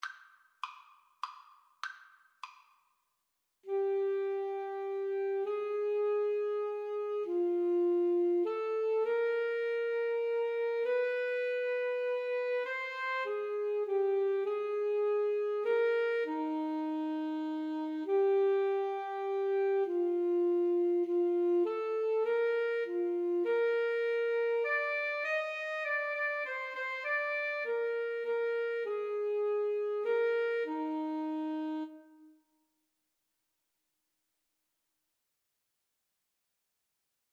FluteAlto Saxophone
Moderato
3/4 (View more 3/4 Music)